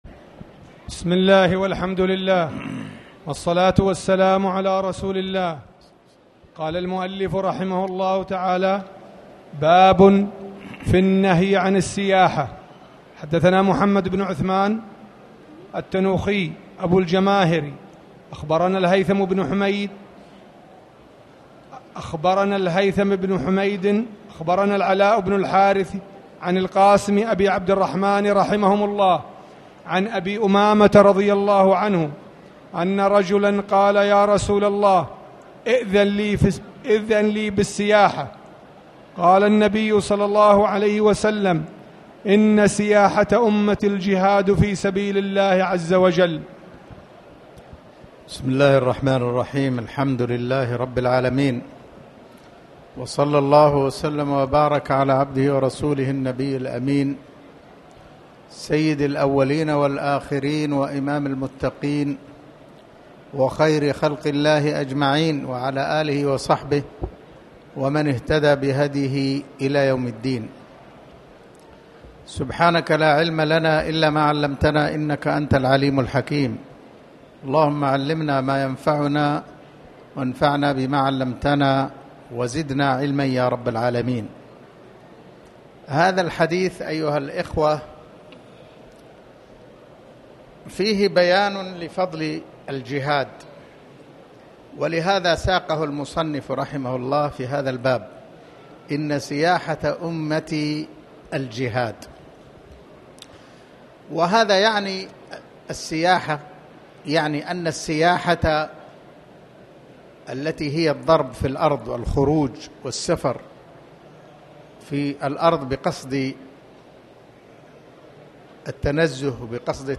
تاريخ النشر ١٨ شوال ١٤٣٨ هـ المكان: المسجد الحرام الشيخ